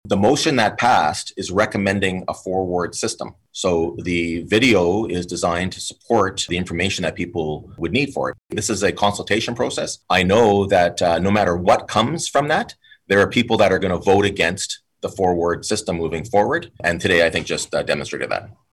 Mayor Mitch Panciuk responded that the video was in line with the motion that council approved in May and felt that some councillors were using it to further debate on the ward change proposal itself.